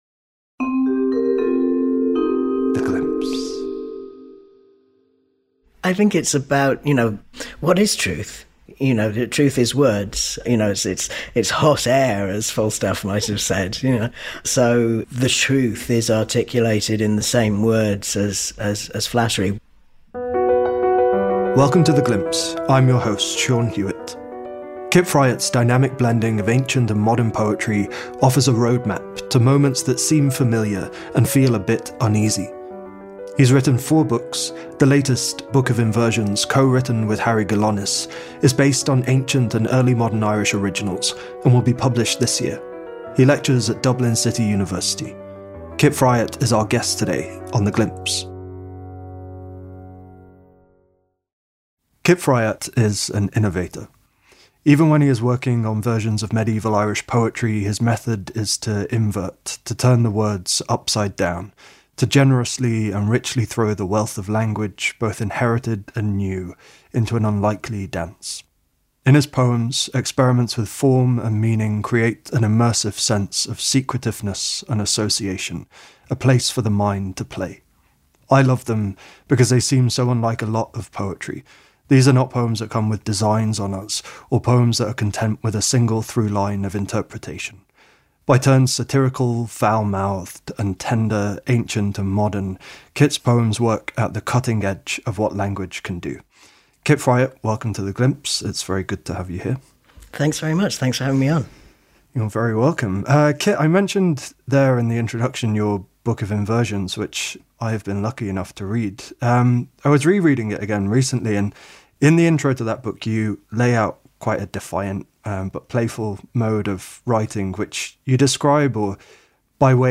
Note: Thomas Wyatt's poem is presented in its original form.